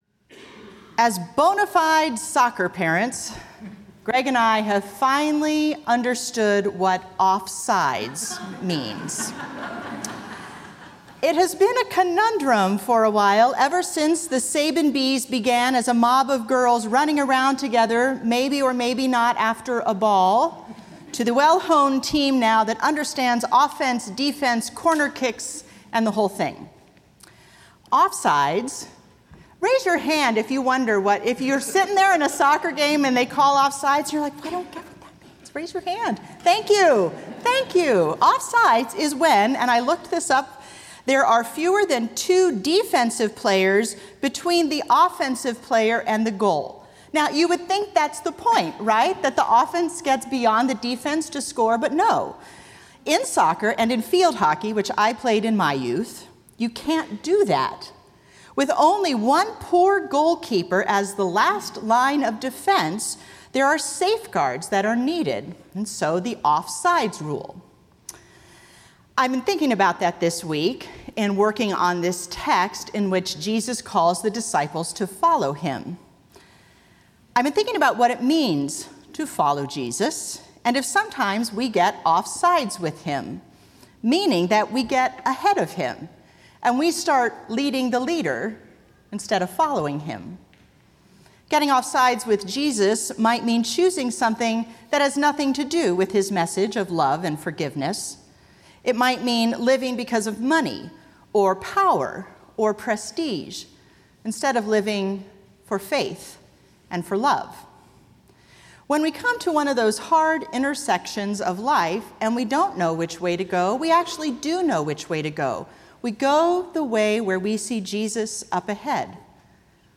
Sermon As bona fide soccer parents